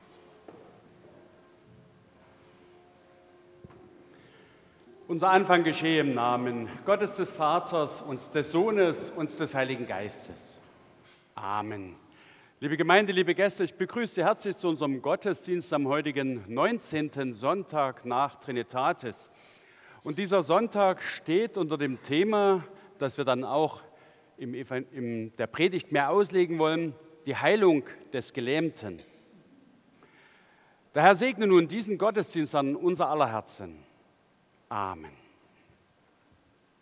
1. Begrüßung
Audiomitschnitt unseres Gottesdienstes vom 19. Sonntag nach Trinitatis 2025.